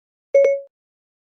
airpods-siri.mp3